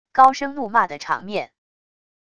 高声怒骂的场面wav音频